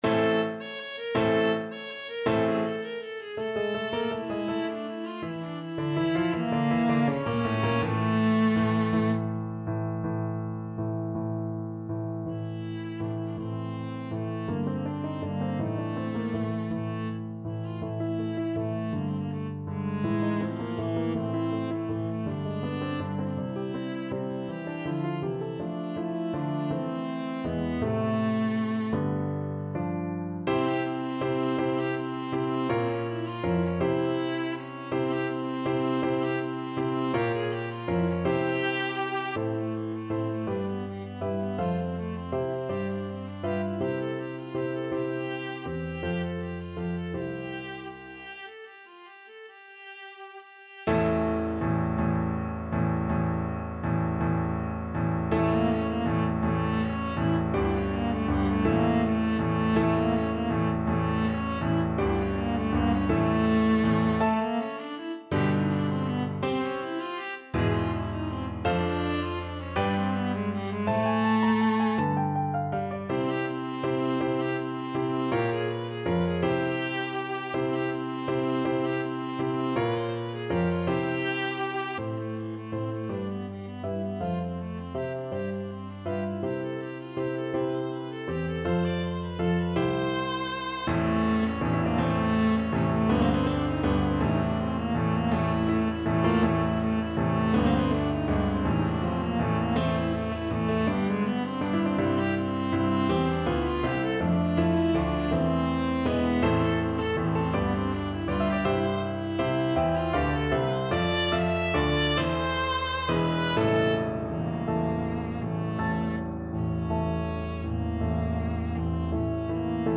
3/4 (View more 3/4 Music)
E4-E6
One in a bar . = c.54